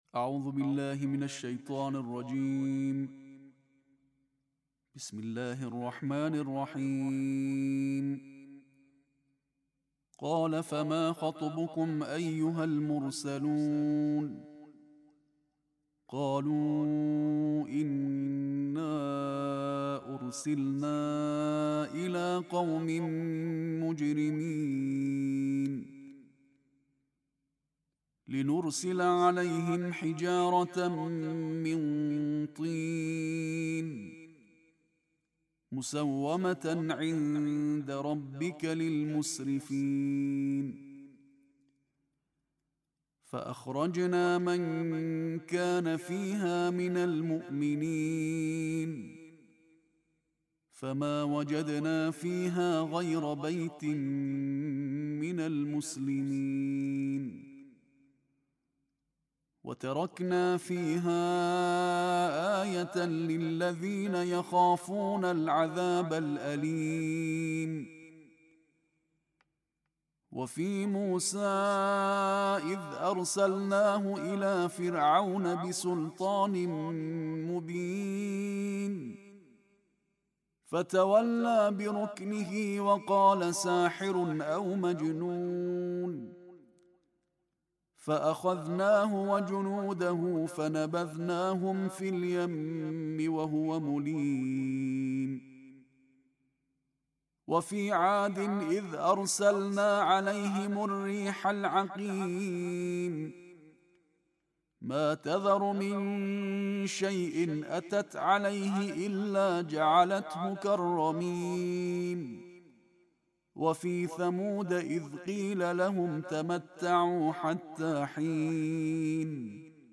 Récitation en tarteel de la 27e partie du Coran